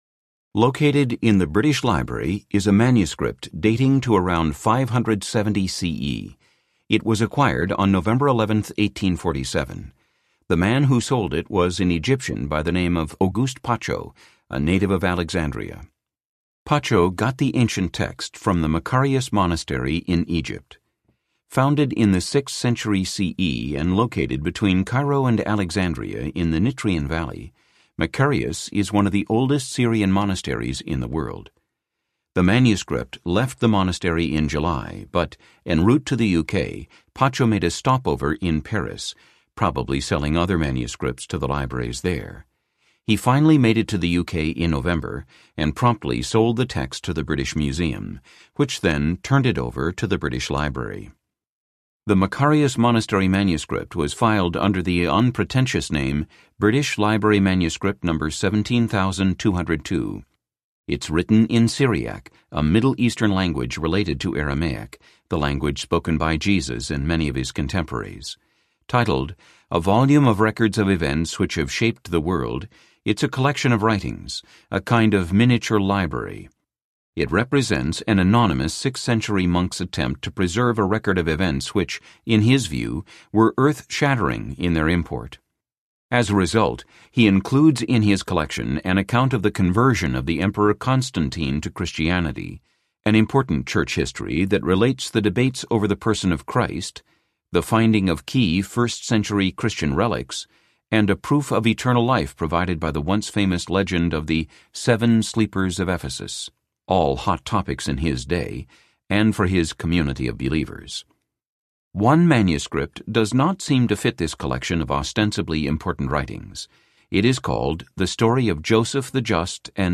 The Lost Gospel Audiobook
Narrator
12.9 Hrs. – Unabridged